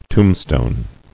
[Tomb·stone]